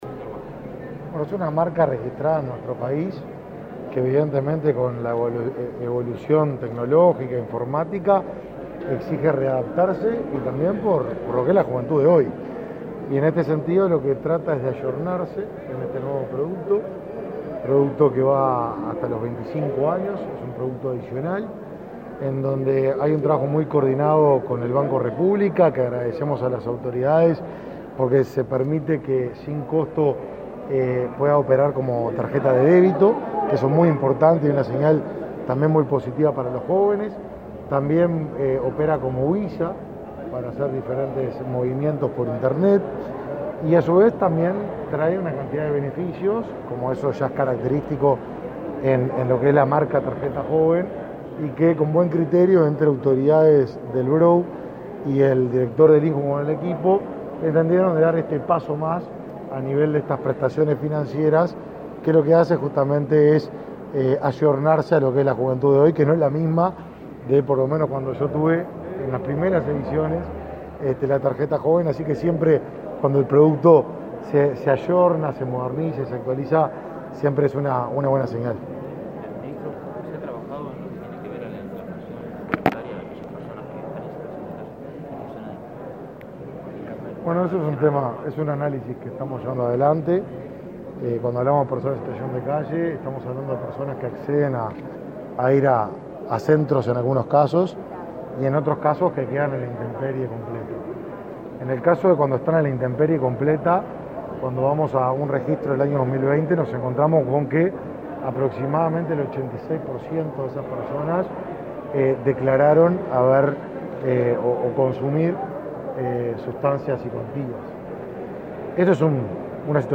Declaraciones a la prensa del ministro de Desarrollo Social